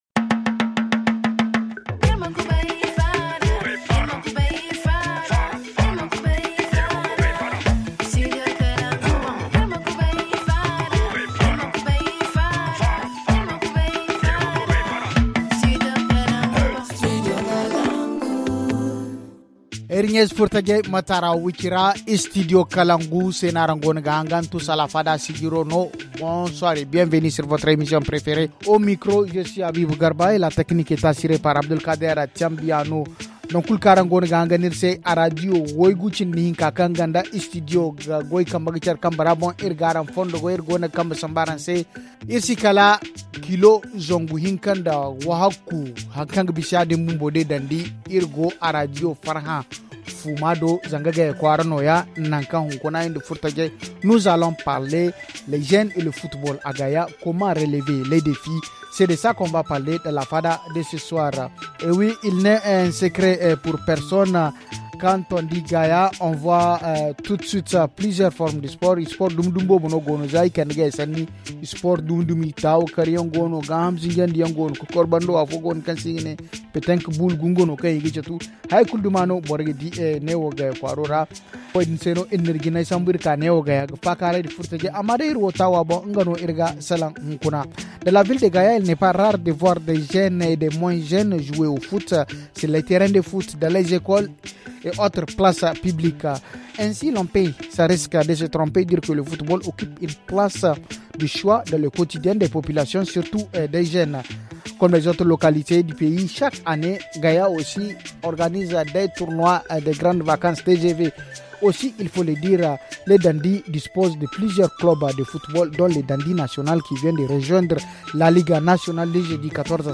Pour en parler nous nous sommes installés dans une école qui sert de terrain de foot aux jeunes de Gaya.